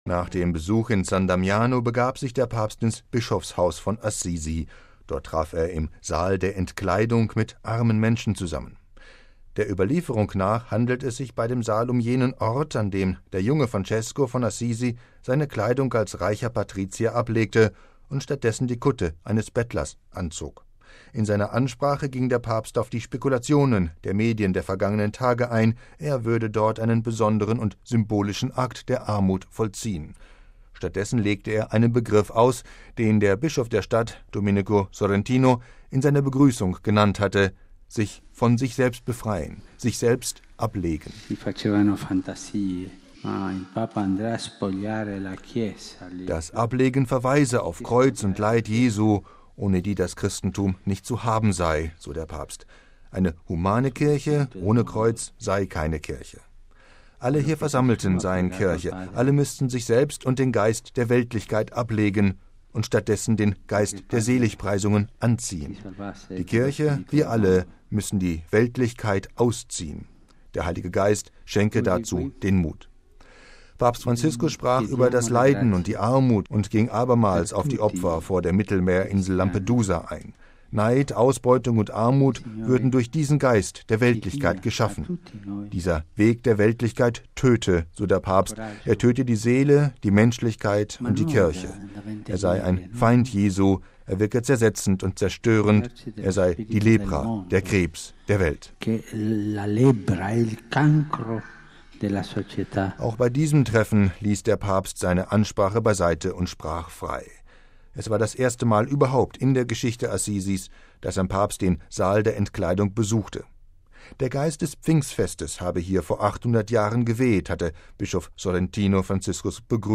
Auch bei diesem Treffen ließ der Papst seine Ansprache beiseite und sprach frei.